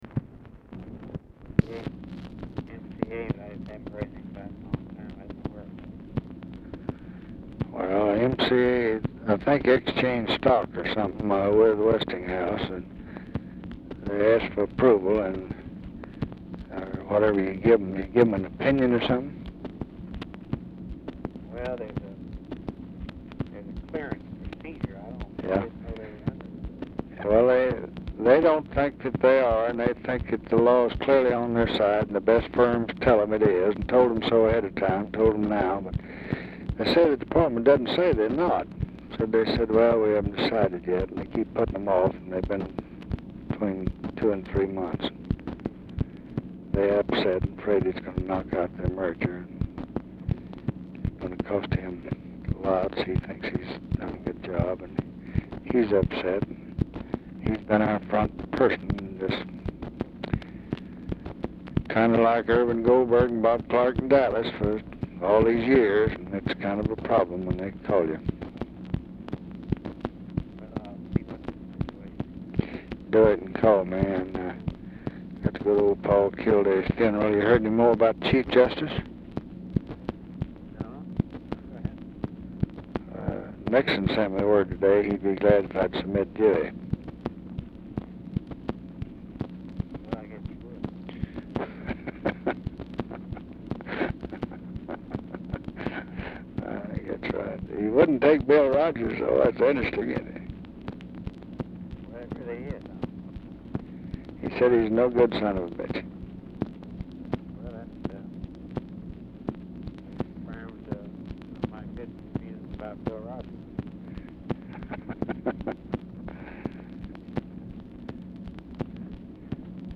RECORDING STARTS AFTER CONVERSATION HAS BEGUN; CLARK IS DIFFICULT TO HEAR
Format Dictation belt
Location Of Speaker 1 Mansion, White House, Washington, DC
Specific Item Type Telephone conversation